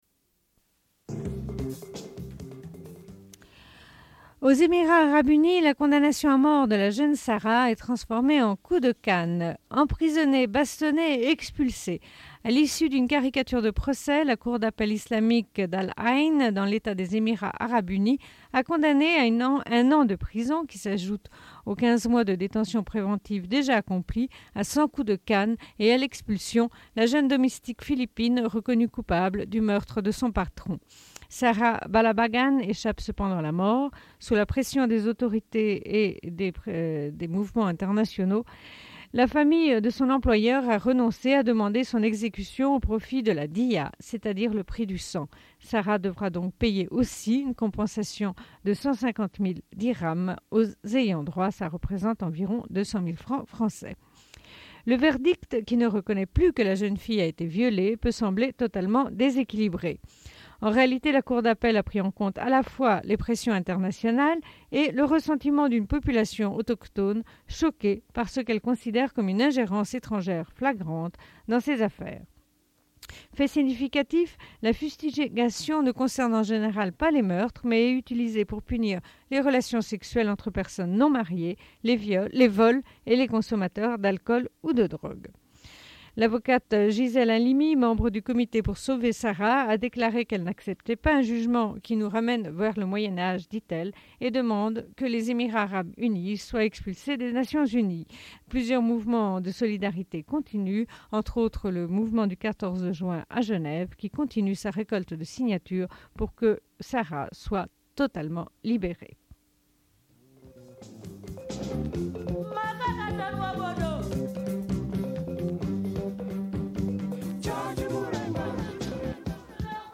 Bulletin d'information de Radio Pleine Lune
Une cassette audio, face B
Radio Enregistrement sonore